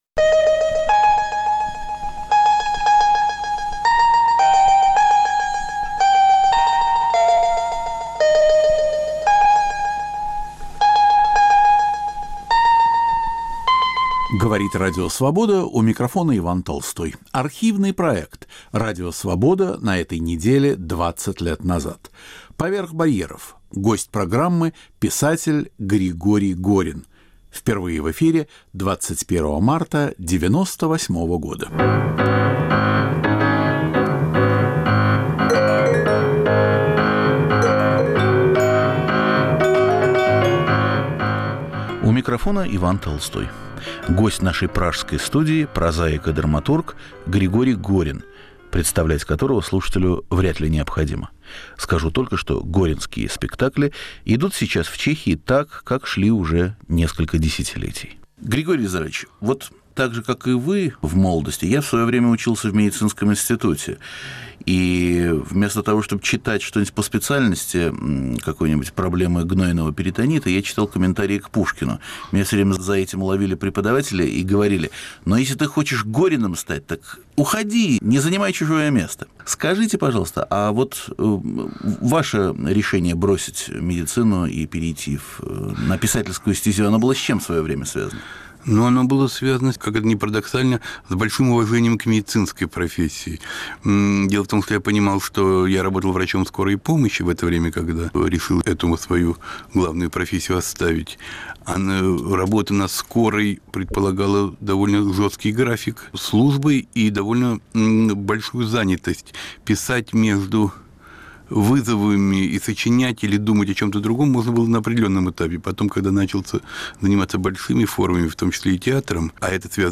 Радио Свобода на этой неделе 20 лет назад. В студии писатель Григорий Горин
Архивный проект. Иван Толстой выбирает из нашего эфира по-прежнему актуальное и оказавшееся вечным.